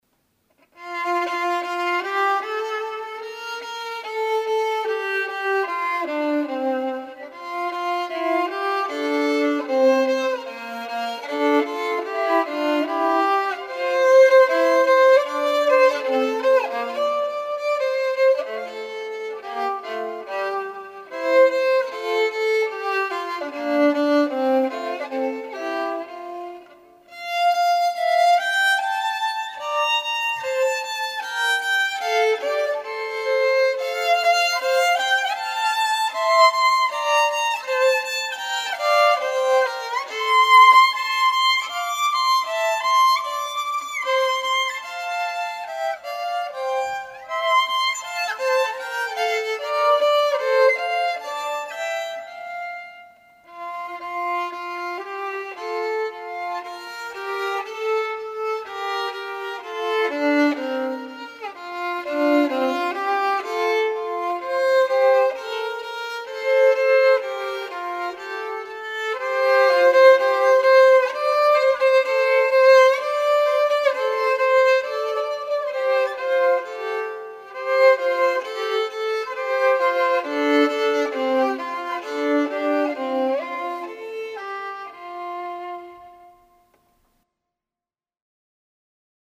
何とカノンに挑戦した３番をつけてみました！